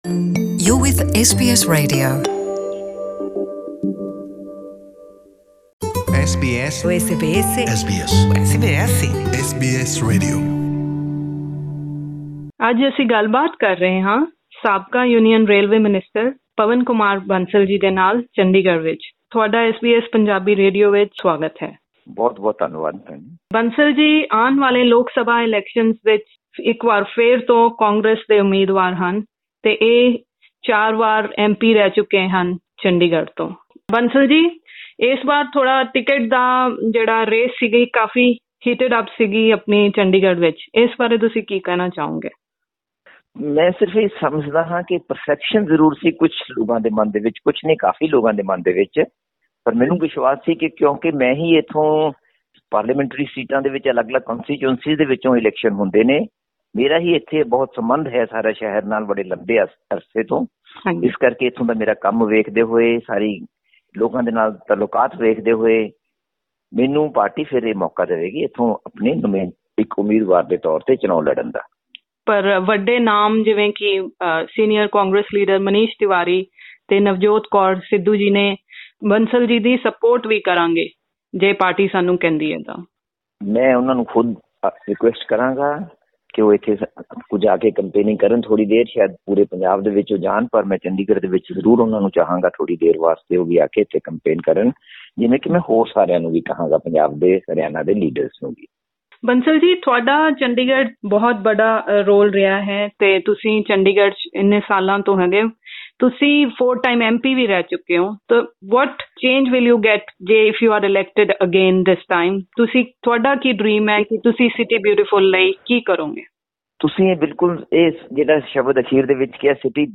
In an interview with SBS Punjabi, Mr Bansal invited Dr Navjot Kaur Sidhu, along with other Punjab and Haryana leaders to campaign for him in his electorate.